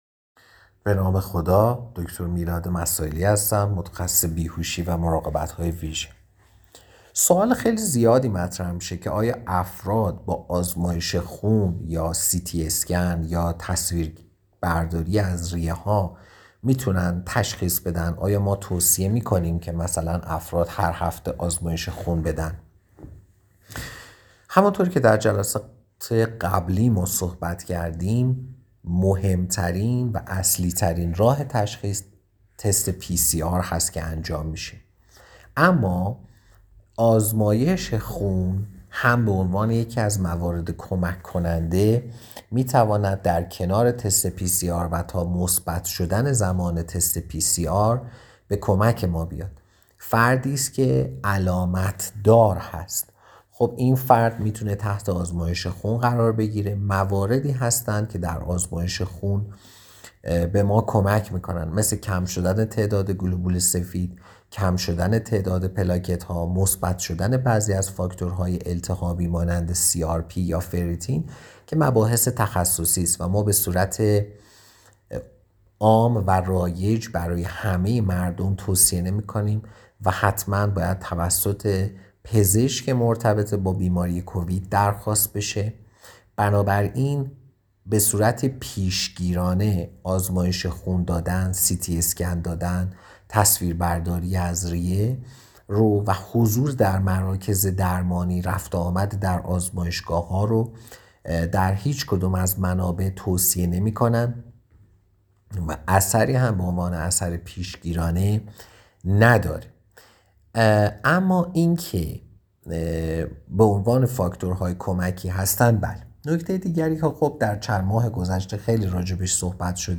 یک متخصص: